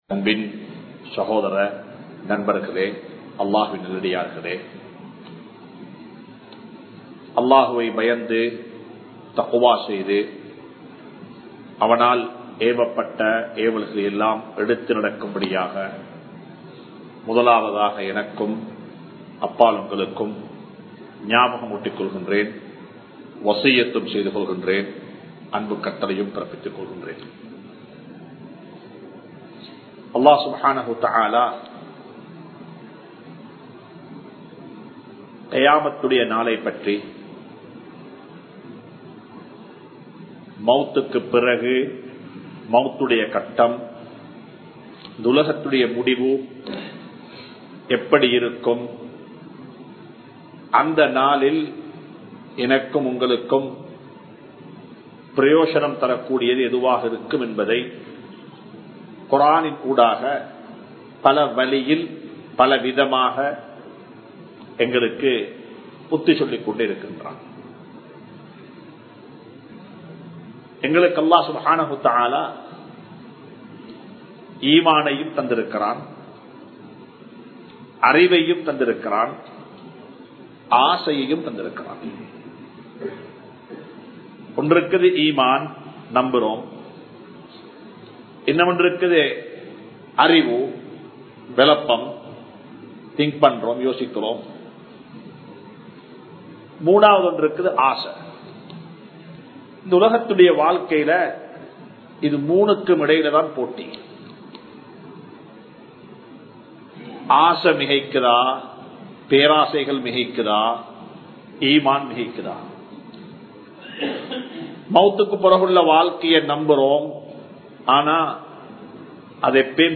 Ilm & Amalhal (இல்ம் & அமல்கள்) | Audio Bayans | All Ceylon Muslim Youth Community | Addalaichenai